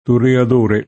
toreaD0r] s. m.; pl. toreadores [toreaD1reS] — voce sp., ma, in quella lingua, disusata e sostituita da torero; nota in It. più che altro per la celebre aria della «Carmen» di G. Bizet — un adattam. toreadore [